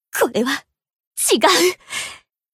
BA_V_Aru_Battle_Damage_3.ogg